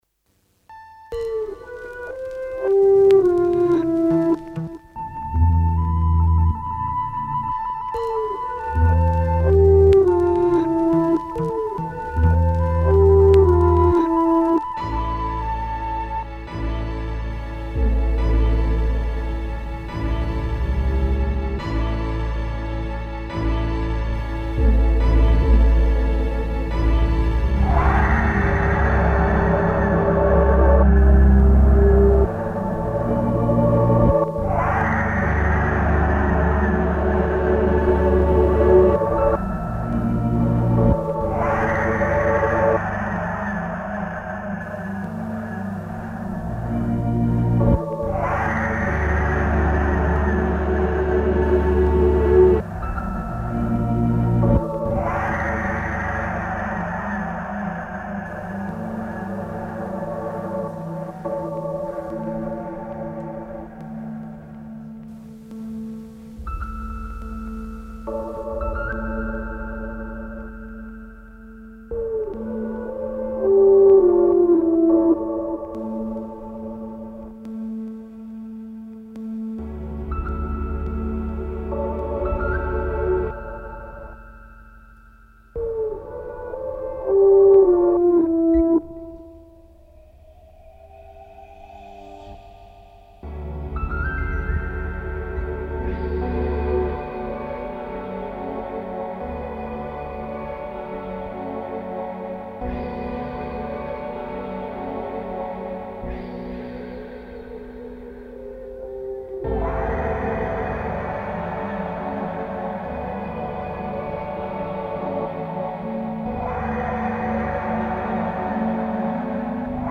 металлофоны, виолончели, арфы, гитары, мандолины